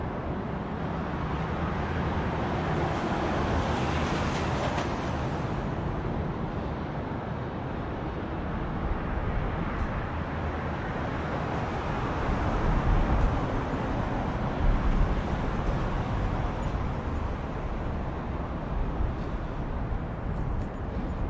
环境 " 交通立交桥延长线短
描述：我的掌上电脑录音机在高速公路上通过录音。
标签： 公路 汽车 交通 街道 汽车 立交桥 噪音 城市
声道立体声